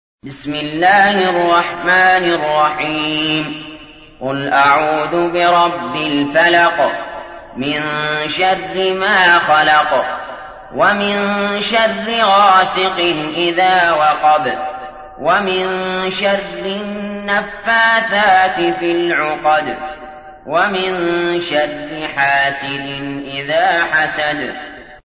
تحميل : 113. سورة الفلق / القارئ علي جابر / القرآن الكريم / موقع يا حسين